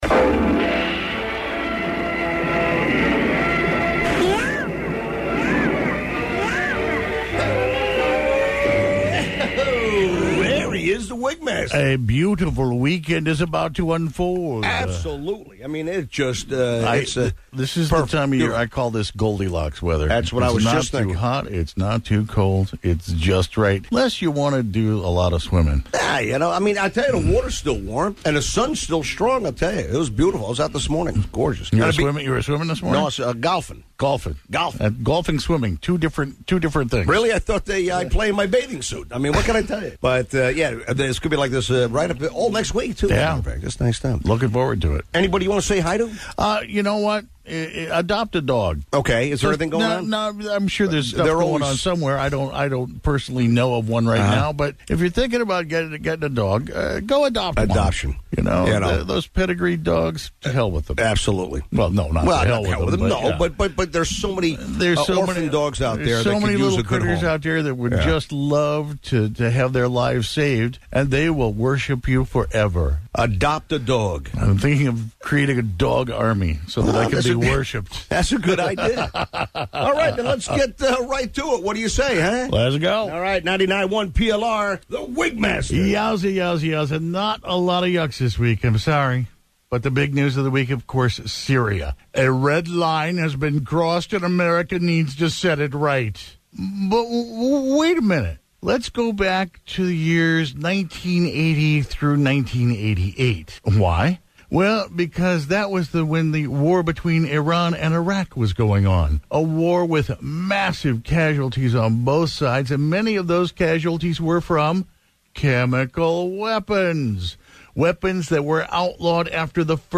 Live Wigout